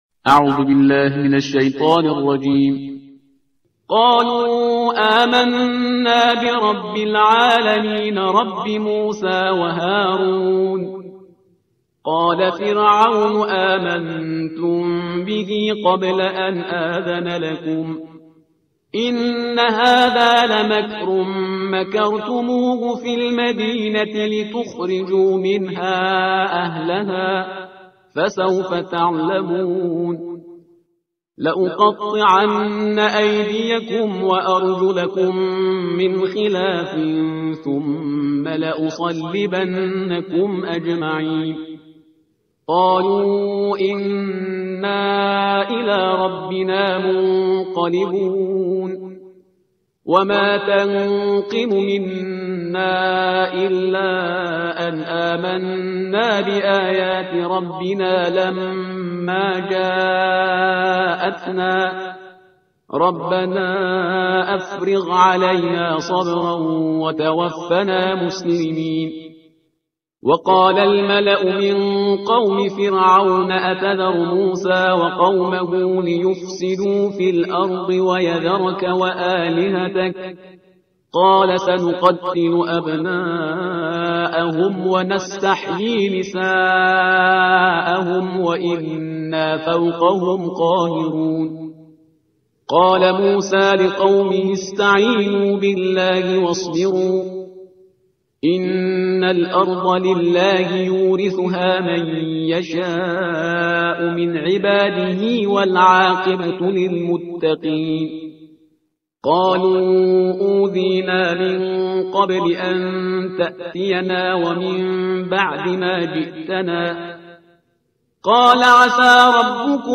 ترتیل صفحه 165 قرآن با صدای شهریار پرهیزگار